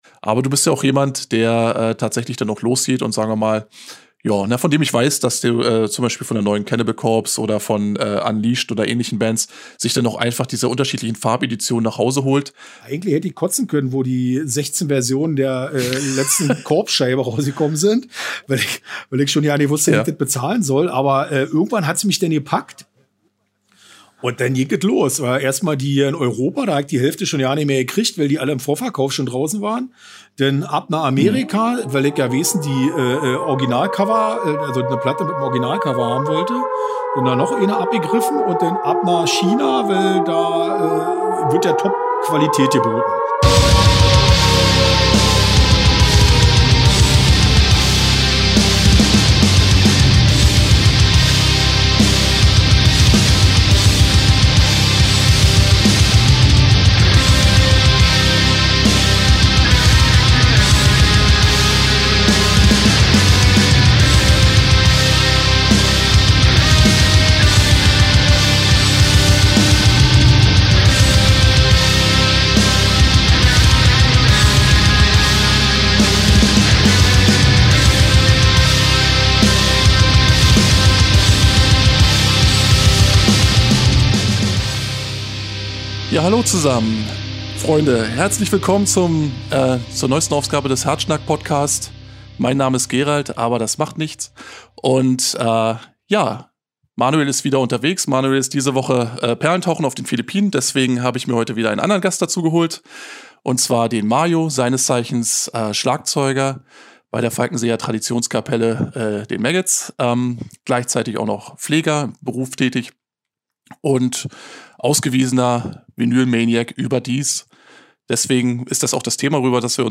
Und warum muss man unbedingt alle Farbversionen einer Vinylveröffentlichung haben und wo bekommt man die am günstigsten her? Es wird nerdig, leicht berlinerisch und sehr informativ.